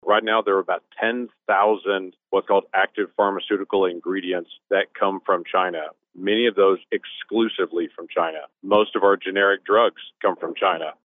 CLICK HERE to listen to details from Senator James Lankford.